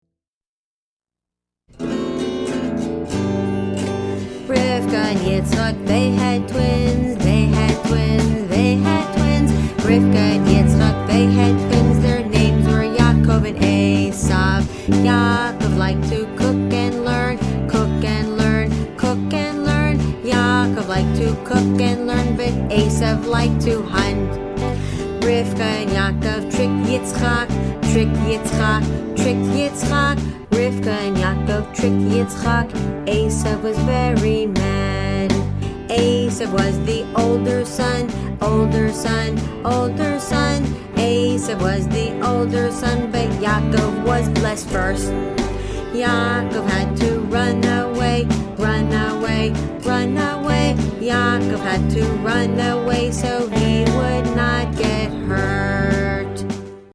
we are preschool teachers, not professional singers )